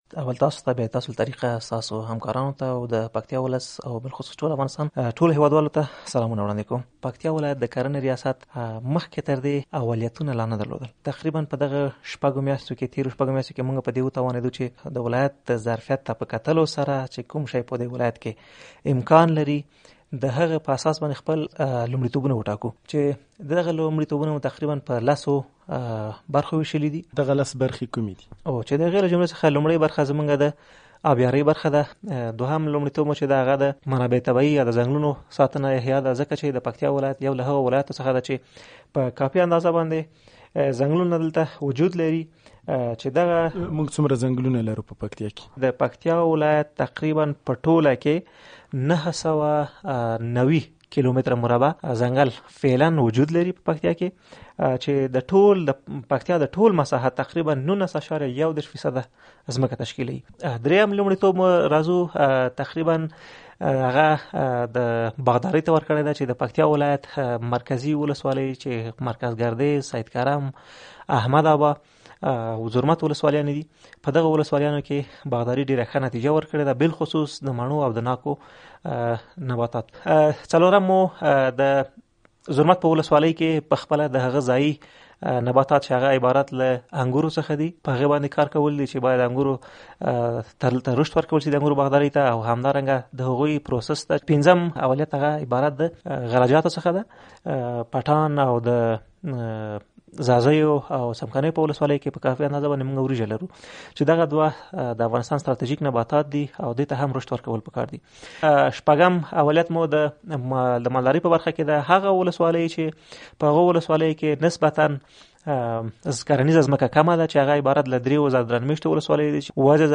مرکه
له ښاغلي تسل سره مرکه